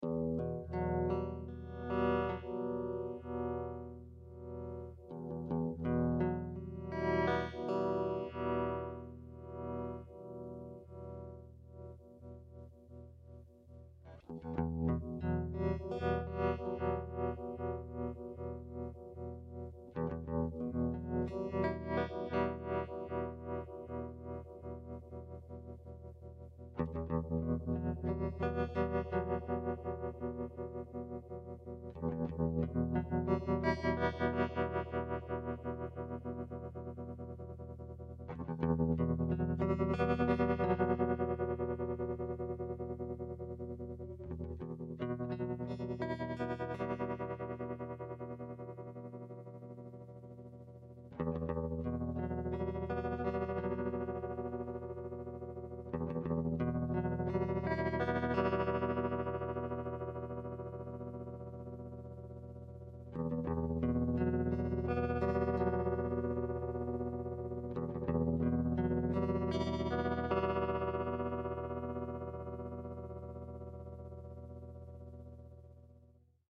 You'll notice how the vibrato clips are louder than the phasor ones - that's because I was lazy in my prototyping and didn't include attenuation on the vibrato outputs, normally they are level matched ...
STEREO-mode Sound Clips
Test conditions: "Tribute" ASAT Classic Tele >> SV-2 >> EMU 1212 soundcard - of course it sounds a little different plugged into real amplifiers ... the clips are raw - there's no compression or verb ...
stereo vibrato sine.mp3